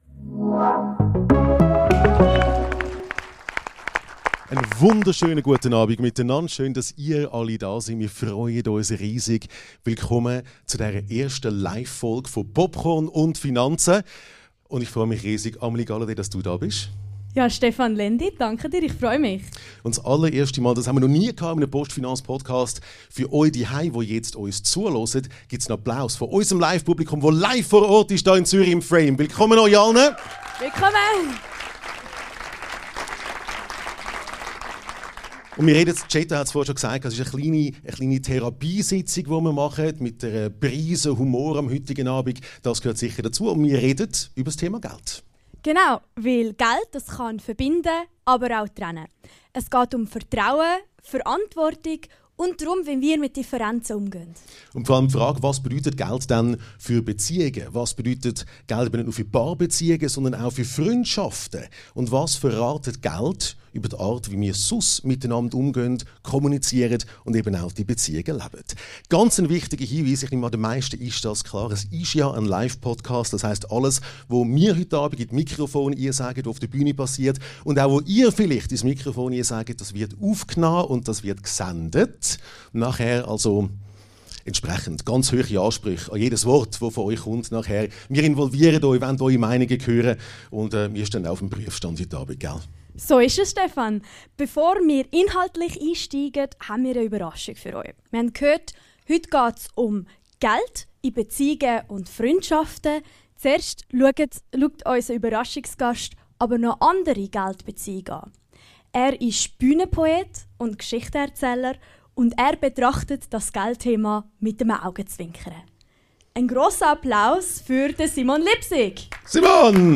Das Publikum wird aktiv einbezogen: Paare, Singles und Neugierige teilen offen ihre Erfahrungen, Fragen und Werte. Ein Abend voller Ehrlichkeit, Humor und Aha-Momente und die Erkenntnis: Wer über Geld spricht, spricht eigentlich über Beziehung.